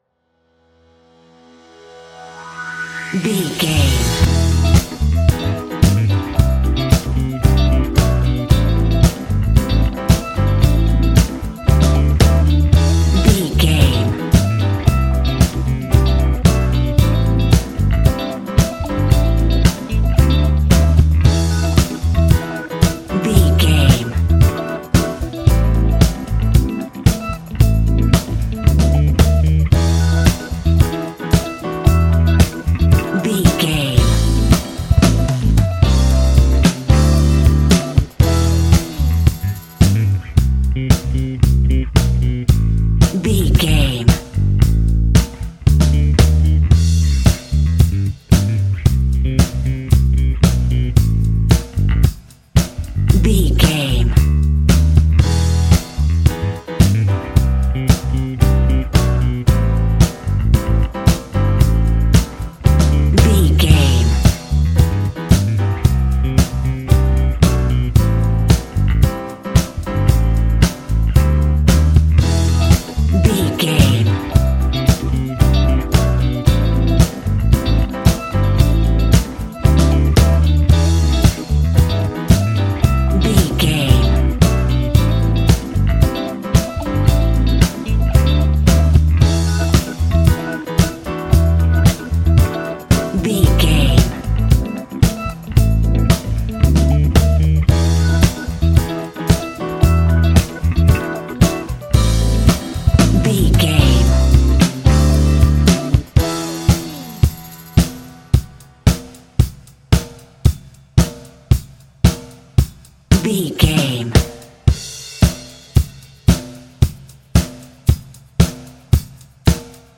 Ionian/Major
A♭
house
synths
techno
trance
instrumentals